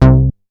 MoogGrabThatB.WAV